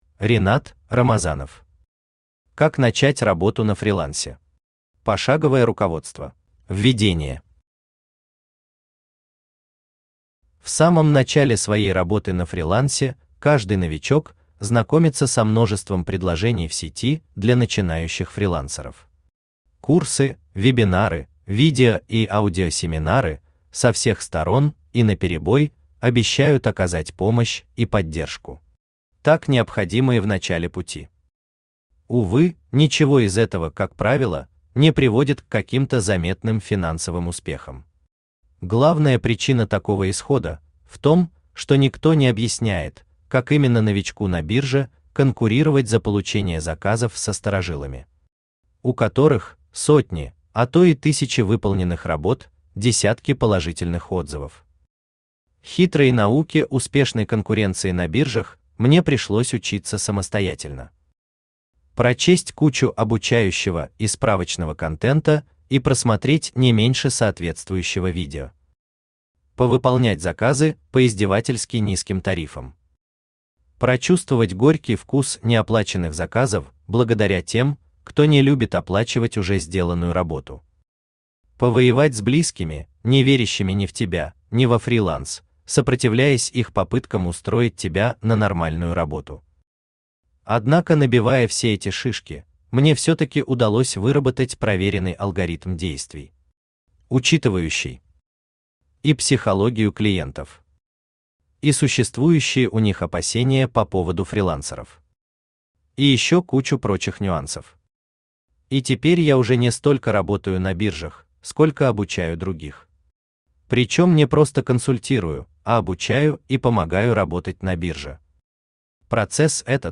Пошаговое руководство Автор Ринат Рамазанов Читает аудиокнигу Авточтец ЛитРес.